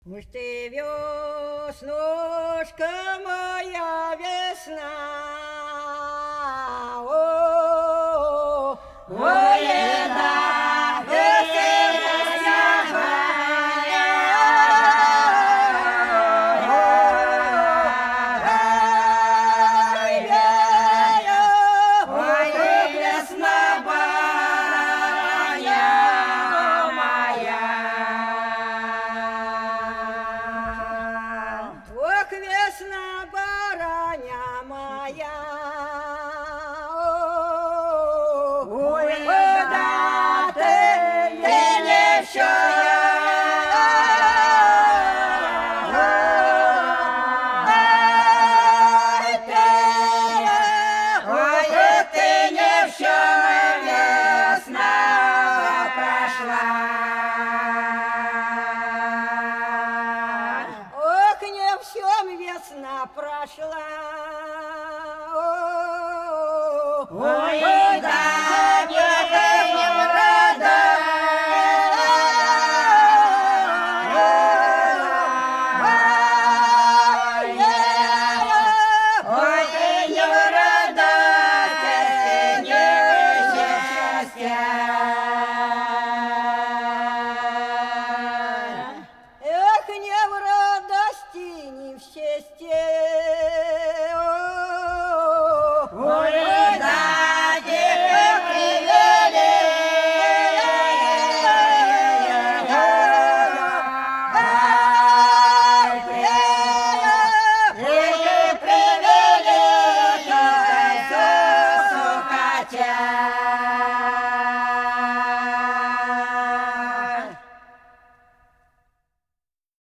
Пролетели все наши года Уж ты веснушка, моя весна – протяжная (Фольклорный ансамбль села Пчелиновка Воронежской области)
19_Уж_ты_веснушка,_моя_весна_–_протяжная.mp3